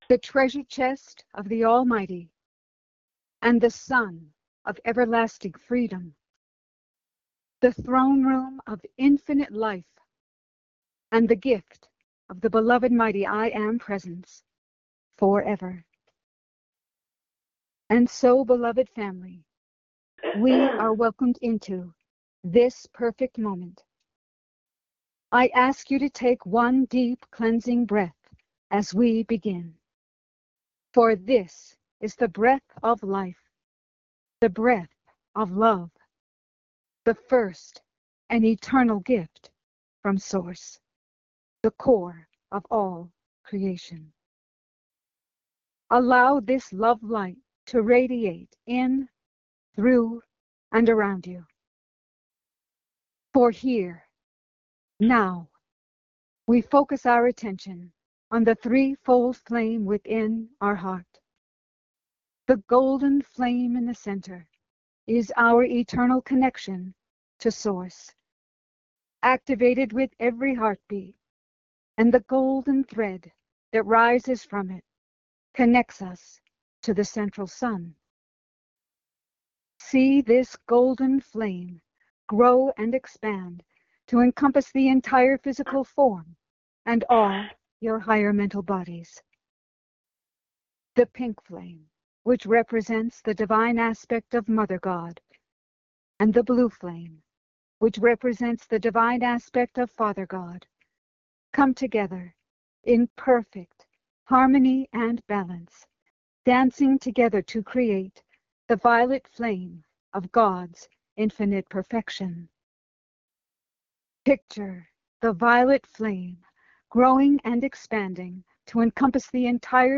Join in group meditation with Lord Sananda.